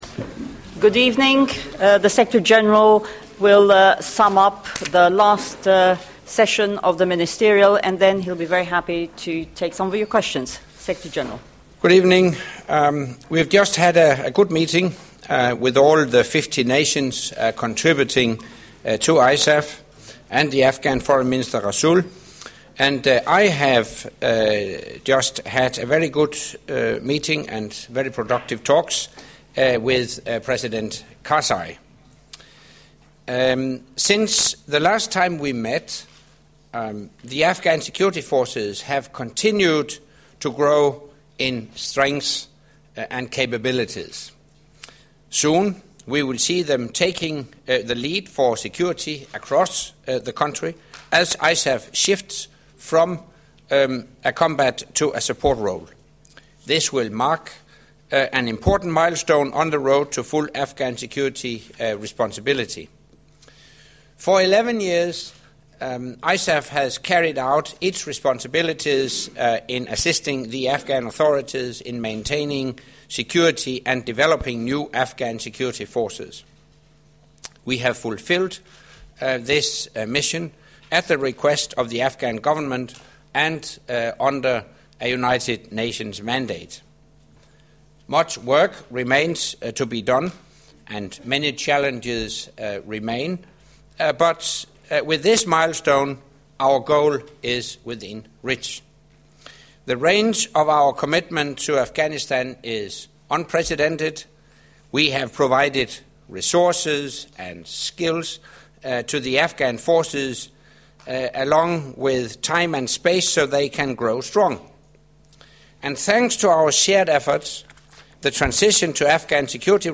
Closing press conference by NATO Secretary General Anders Fogh Rasmussen following the meeting of the NATO Ministers of Foreign Affairs with non-NATO ISAF contributing nations held at NATO HQ